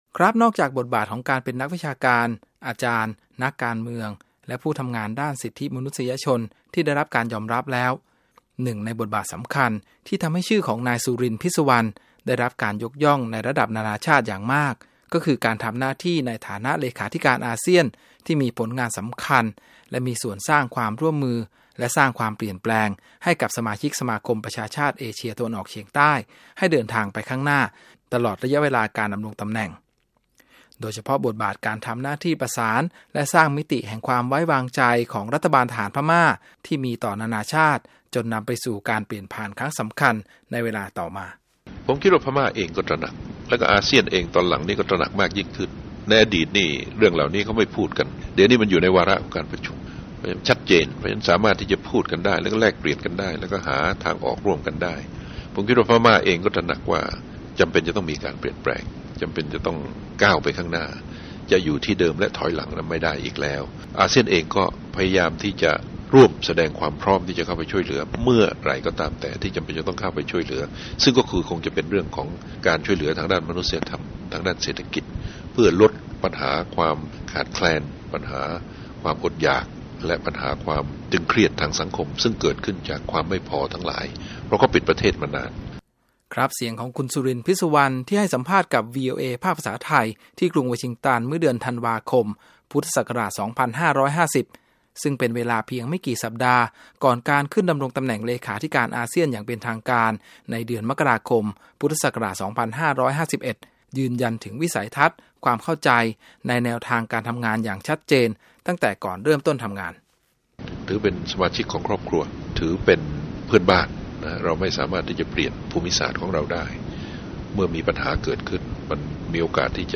ย้อนรำลึกภารกิจและผลงานสำคัญของ ดร.สุรินทร์ พิศสุวรรณ ที่ได้รับการยอมรับจากประชาคมนานาชาติ ในฐานะอดีตเลขาธิการอาเซียนจากประเทศไทย ผ่านบทสัมภาษณ์และมุมมองที่ วีโอเอ ไทยบันทึกไว้เมื่อหลายปีก่อน